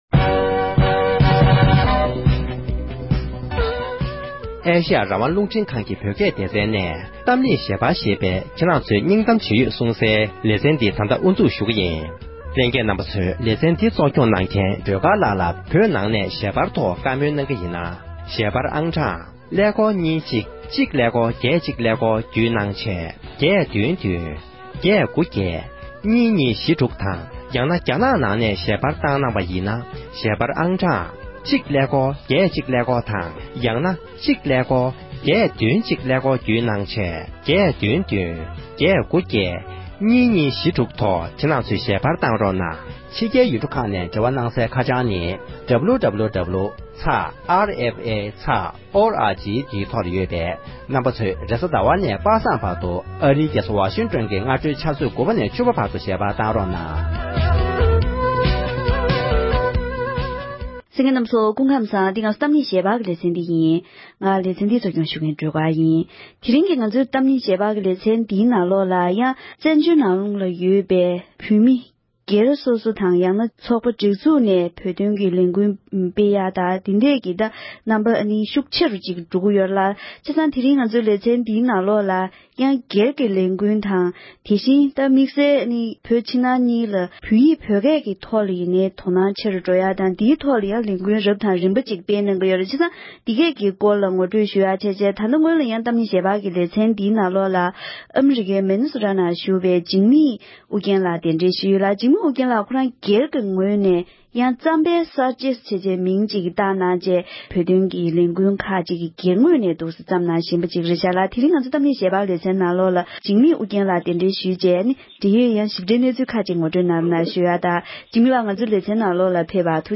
༄༅༎དེ་རིང་གི་གཏམ་གླེང་ཞལ་པར་ཞེས་པའི་ལེ་ཚན་ནང་དུ། བཙན་བྱོལ་ནང་ཡོད་པའི་བོད་པའི་མི་རབས་གསར་པའི་ཁྲོད་ཀྱི་བོད་དོན་ལས་འགུལ་ཁག་གཉིས་ཀྱི་ཐོག་བཀའ་མོལ་ཞུས་པ་ཞིག་གསན་རོགས